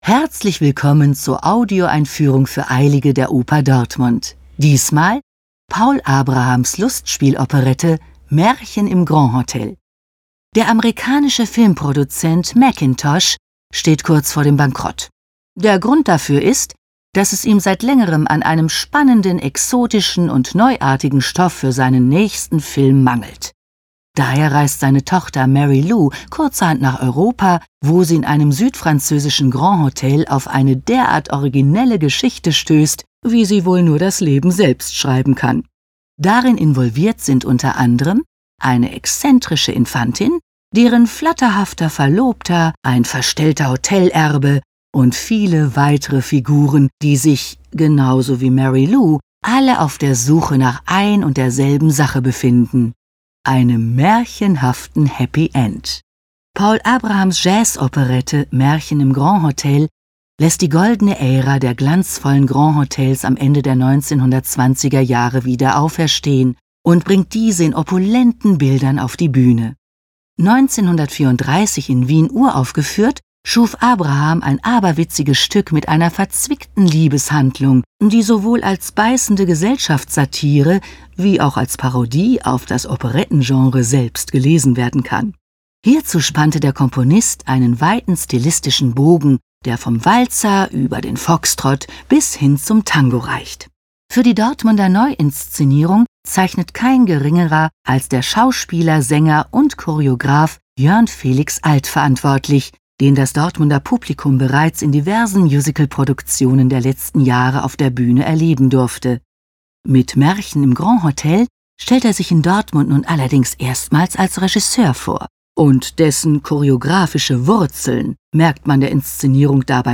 tdo_Audioeinfuehrung_Maerchen_im_Grand-Hotel.mp3